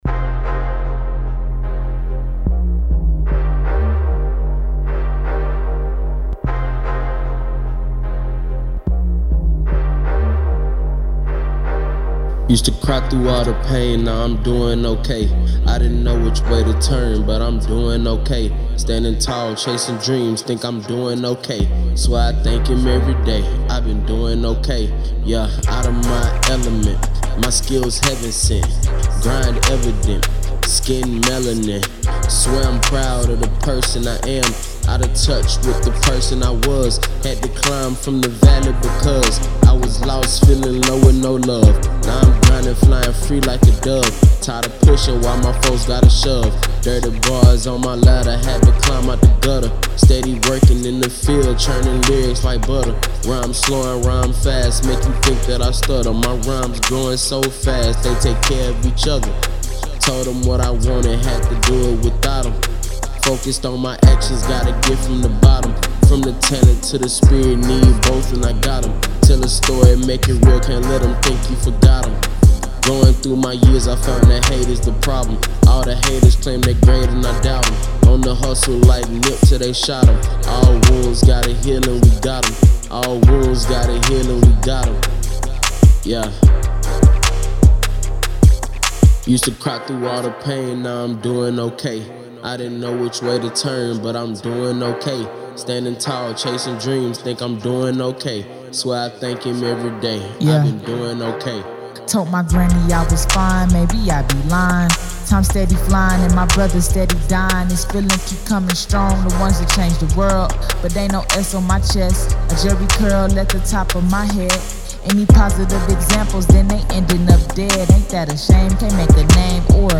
Hiphop Music by Gen iY and Z!